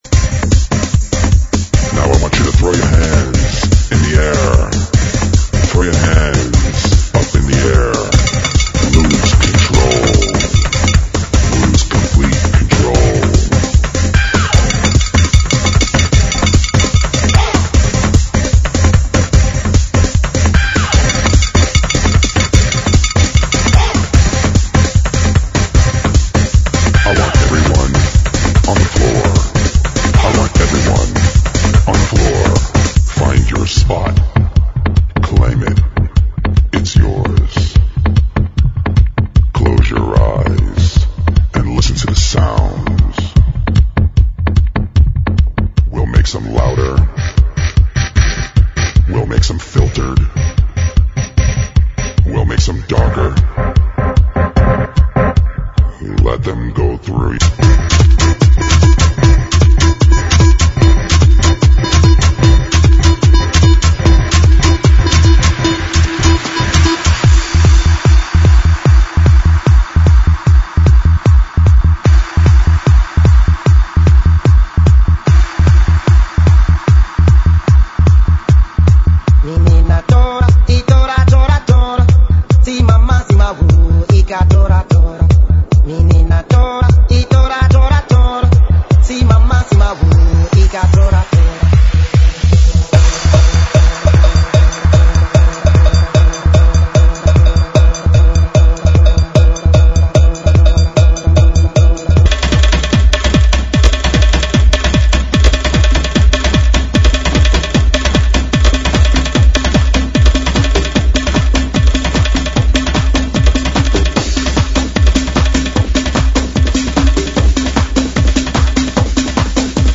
GENERO: ELECTRONICA